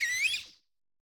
Cri de Zapétrel dans Pokémon Écarlate et Violet.